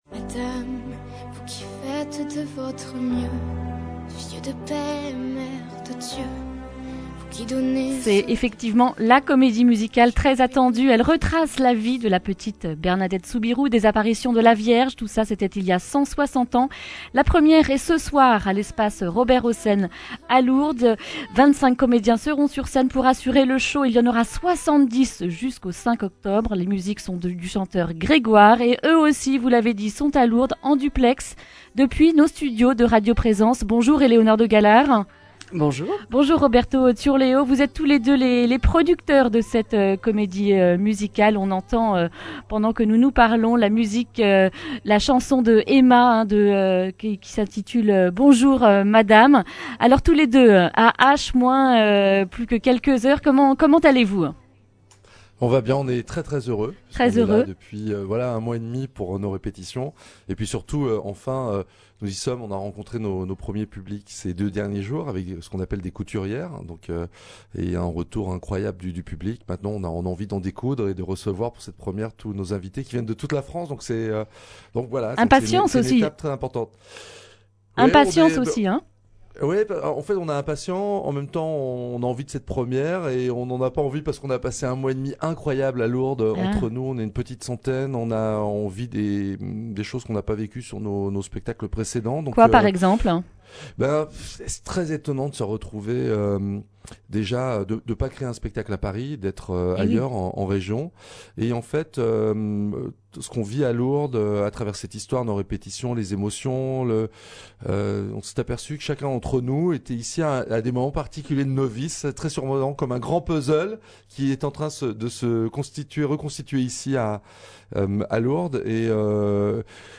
Accueil \ Emissions \ Information \ Régionale \ Le grand entretien \ « Bernadette de Lourdes », la première est ce soir !